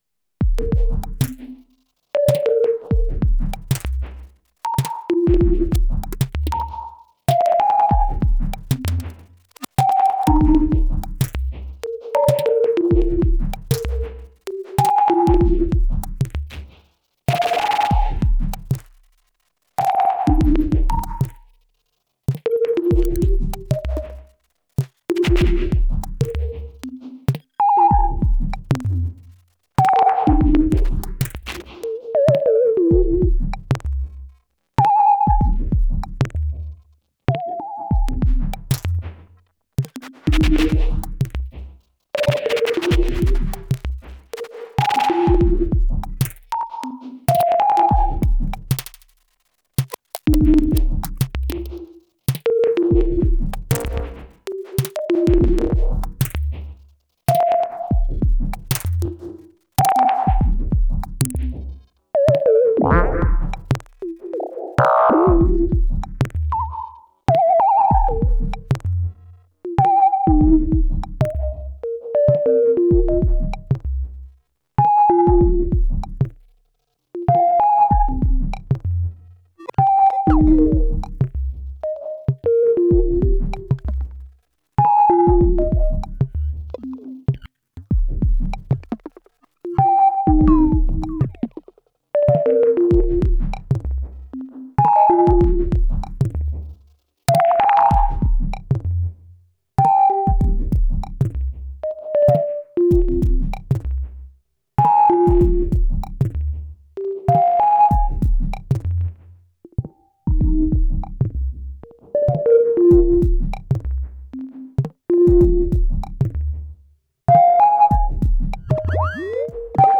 Tried a closed, room sound and some delicate delay tweaking.
Some interesting timbres, when I start ctrl-all